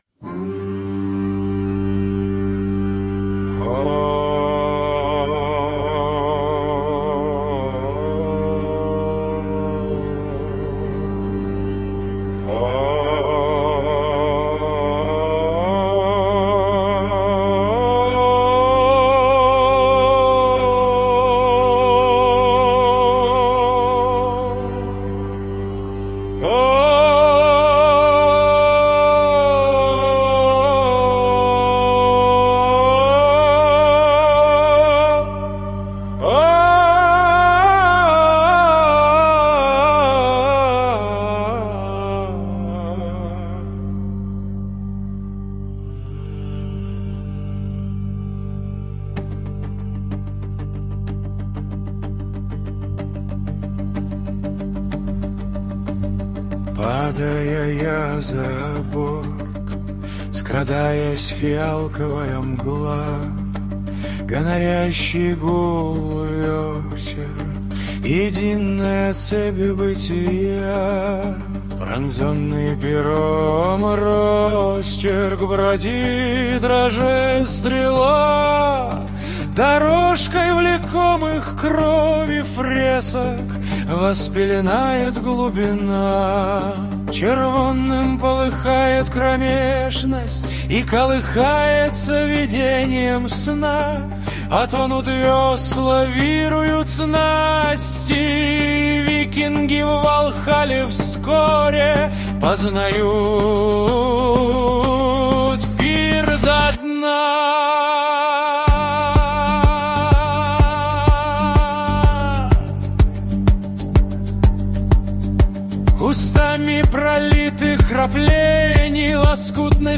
Музыка и исполнение принадлежит ИИ.
ТИП: Пісня
СТИЛЬОВІ ЖАНРИ: Драматичний